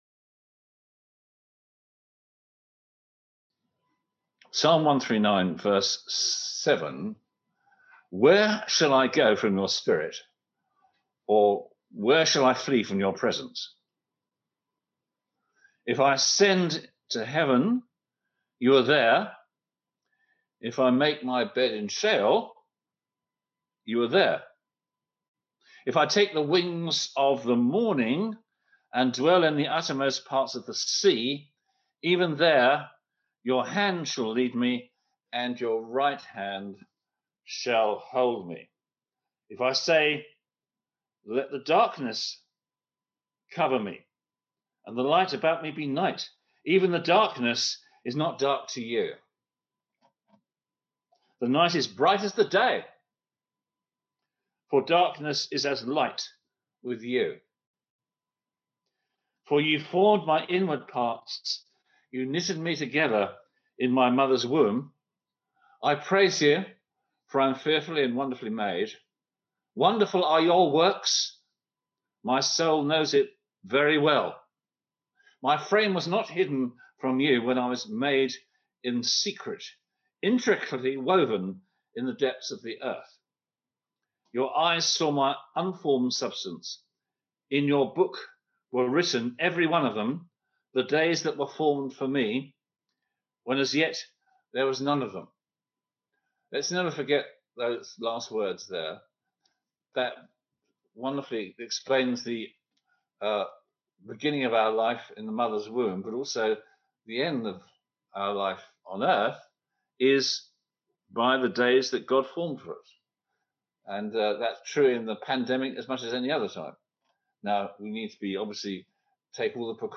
Service Type: On-Line Sunday Service (English)